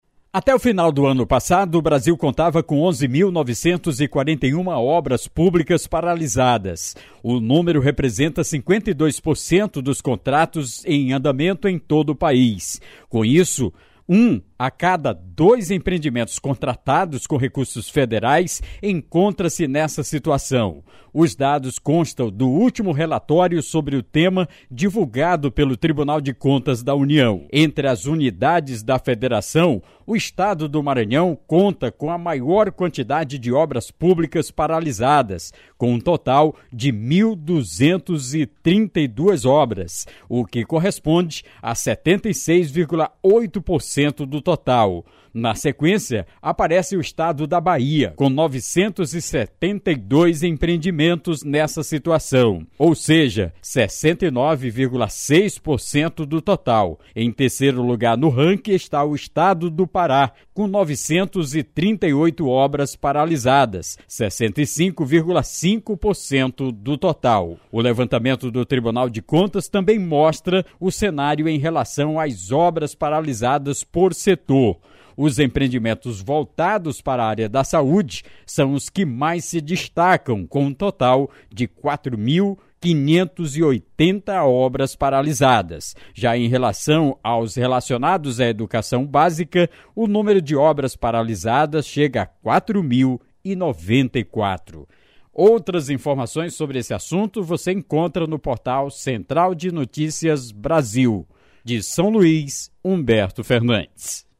Matérias em áudio
Repórter